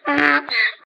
PixelPerfectionCE/assets/minecraft/sounds/mob/horse/donkey/hit3.ogg at 620b52bccc766fc145a08aa8618ead66566ee20a